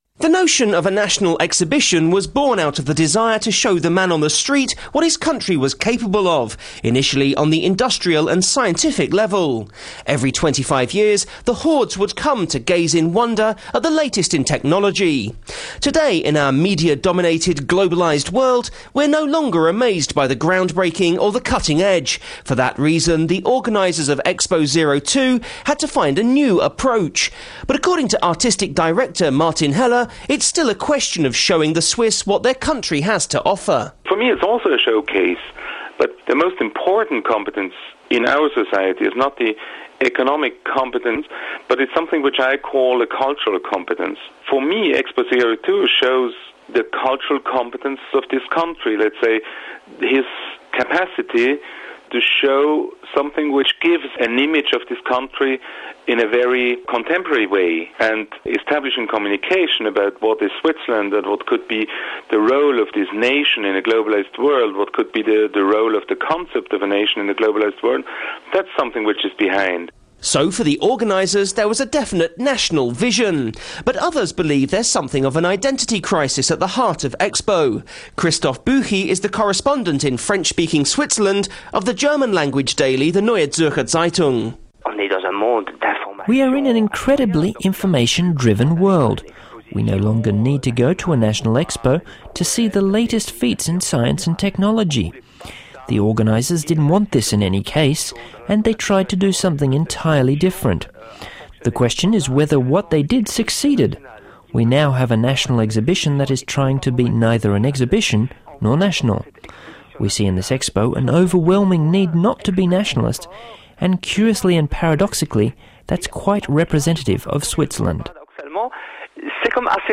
(Swiss Radio International archive in collaboration with Memoriav, 2002)